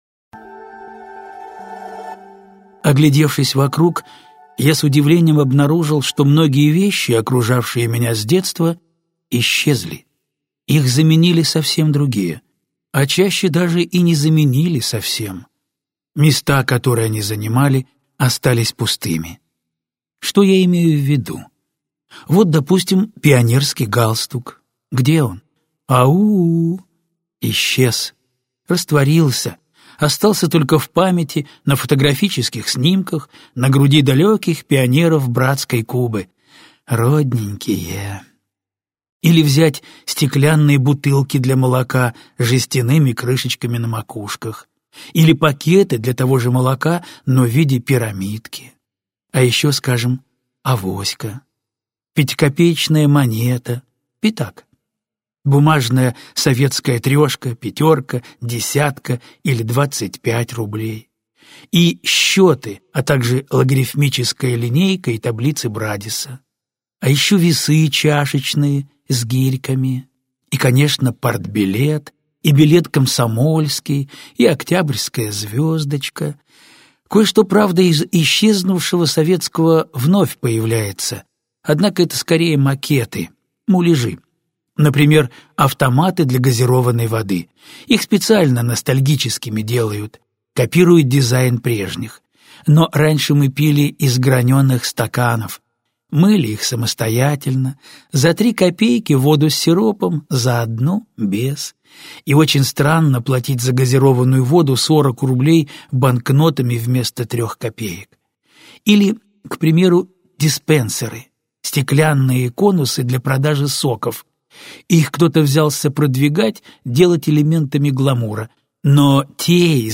Аудиокнига Лавка забытых иллюзий (сборник) - купить, скачать и слушать онлайн | КнигоПоиск